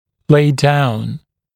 [leɪ daun][лэй даун]откладываться (о кости)